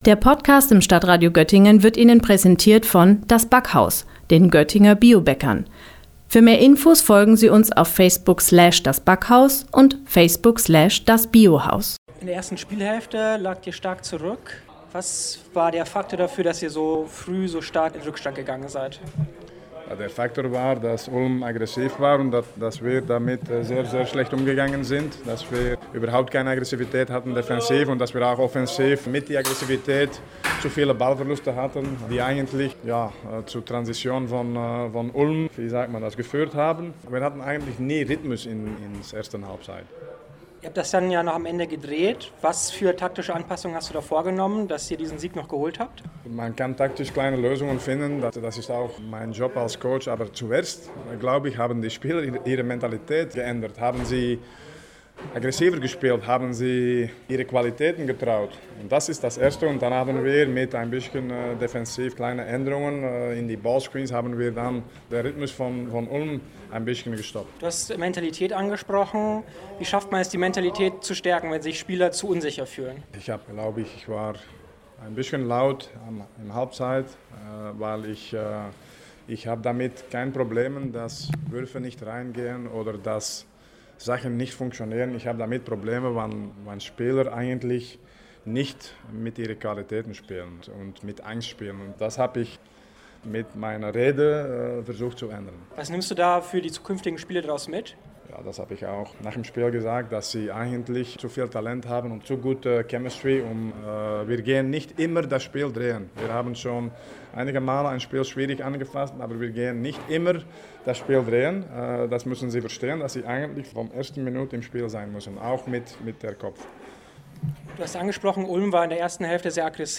Einen Rückstand von Stellenweise 11 Punkten holten die Gastgeber kurz vor Spielende noch auf und gewannen das Nerven zerreißende Spiel 86:82. Unser Reporter